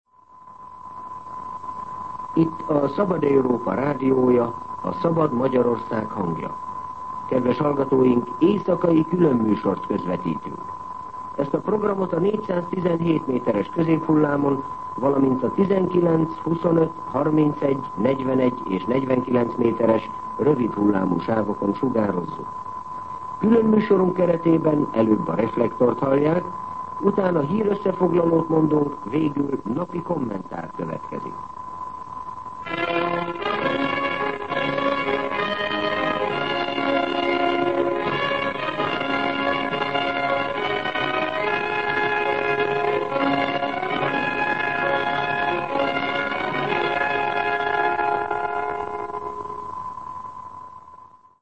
Szignál